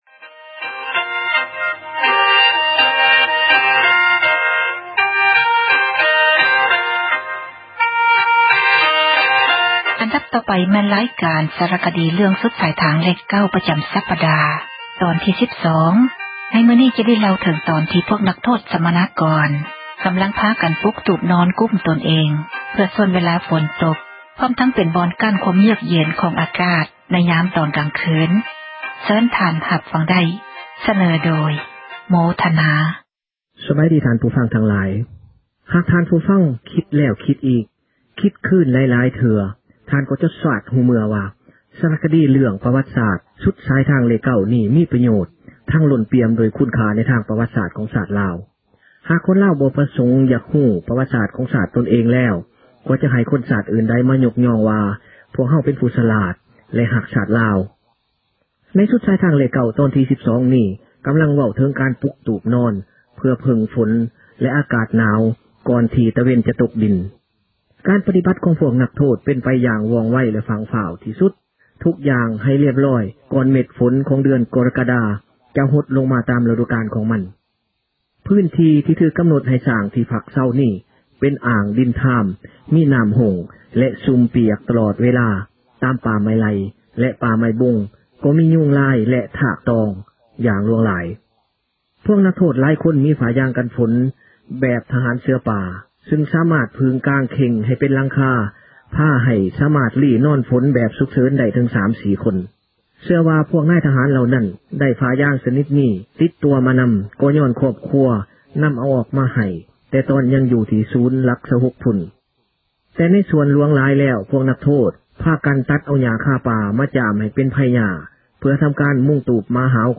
ຣາຍການສາຣະຄະດີ ເຣື້ອງ ”ສຸດສາຍທາງເລຂ 9” ປະຈຳສັປດາ ຕອນທີ 12 ໃນມື້ນີ້ຈະໄດ້ ເລົ່າເຖິງຕອນທີ່ ພວກນັກໂຫດ ສັມມະນາກອນ ພາກັນ ປຸກຕູບ ພໍໄດ້ຂຸກຫົວນອນ ໃນຍາມກາງຄືນ ແລະ ຕອນເດິກຂ້ອນຄືນ ນໍ້າໜອກກໍລົງ ນໍ້າຄ້າງກໍຕົກ ບາງຄັ້ງຝົນກໍຕົກ ຊໍ້າເຂົ້າອີກ ປະສົມກັບອາກາດ ທີ່ໜາວເຢັນ ໃນແຕ່ລະຄືນ......